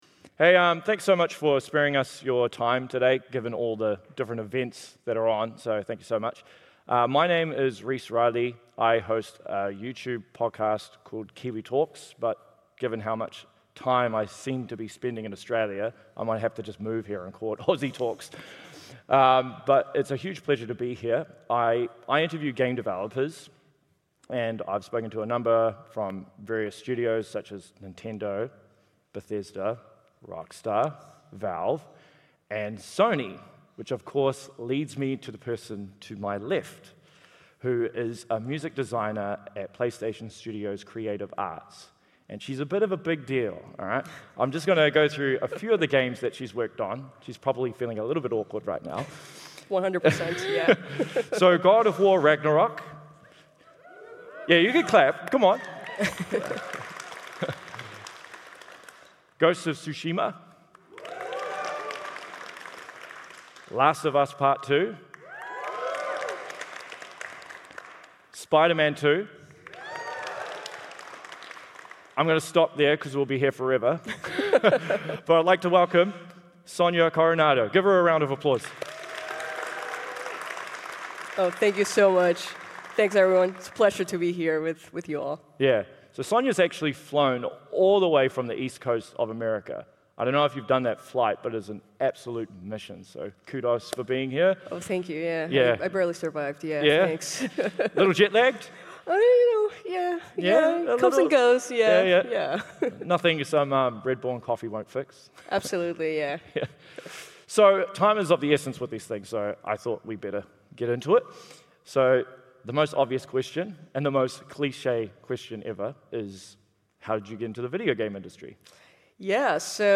Due to NDA's, we had to cover generic things involving her role for Playstation. All content is courtesy of PAX Australia, also thanks to the amazing crowd!